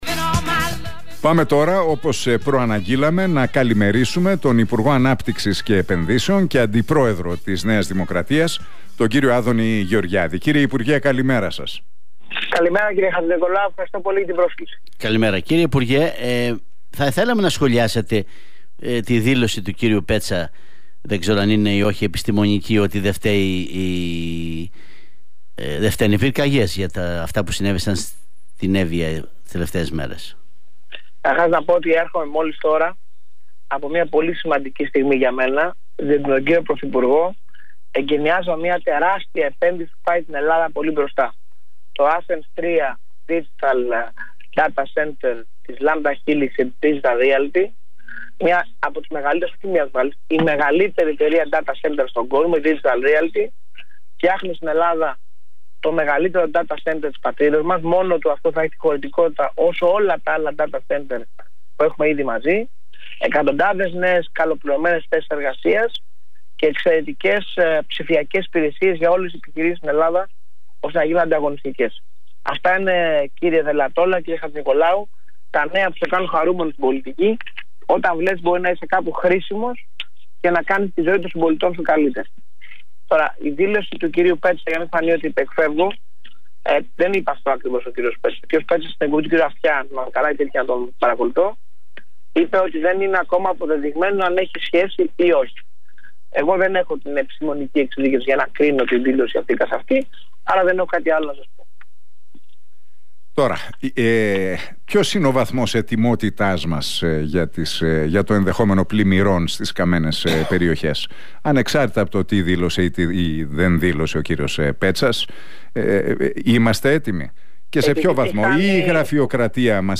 Ο Άδωνις Γεωργιάδης σε συνέντευξή του στον Realfm 97,8